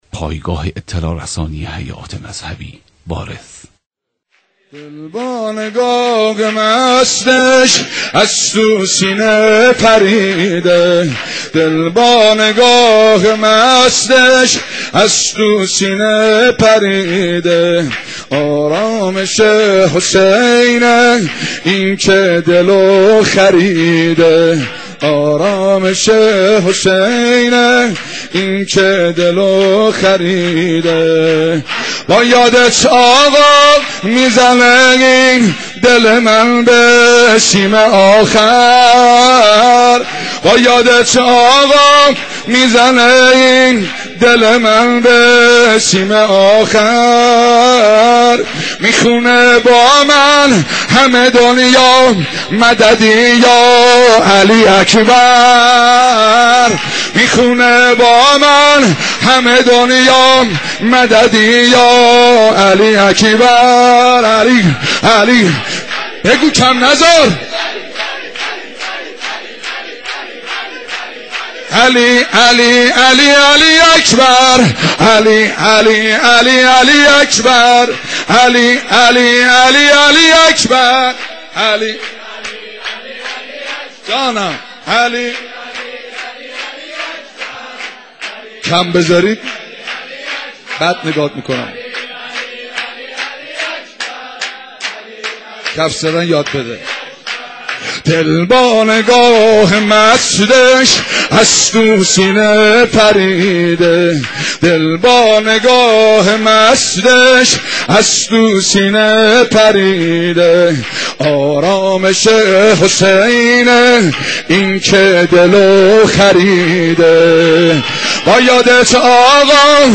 مولودی
هیئت فاطمیون ع